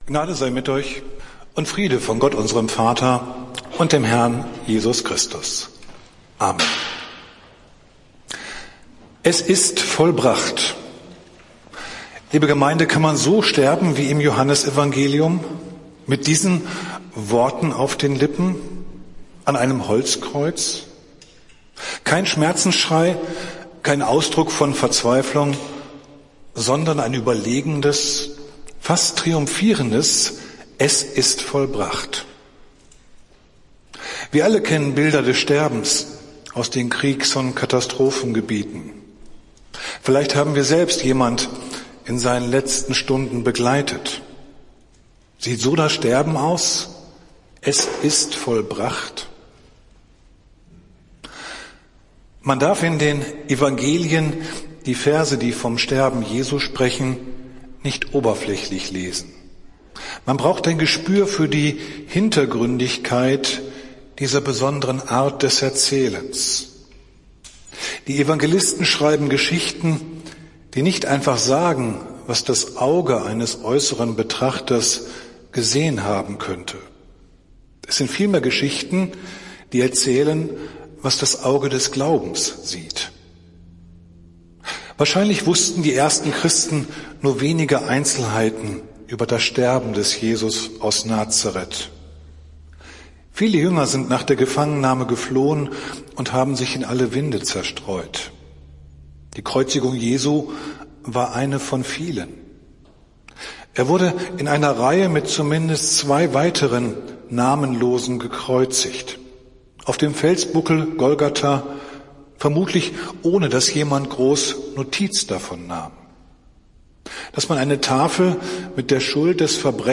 Predigt des Gottesdienstes aus der Zionskirche vom Karfreitag, 07.04.2023, 15:00 Uhr